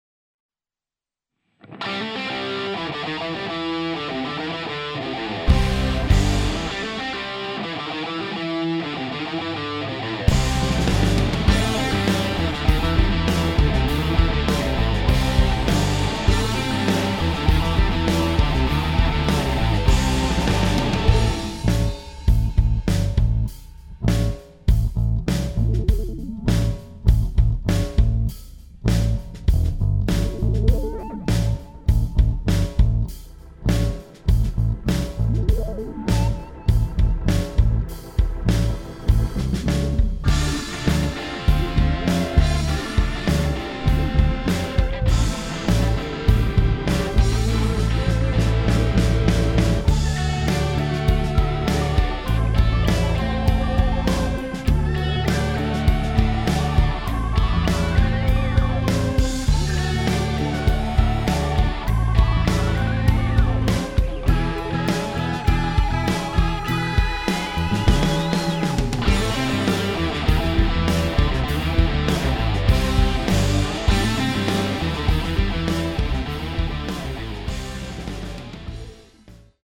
Su questa il basso è elaborato dalla catena RND535+RND551.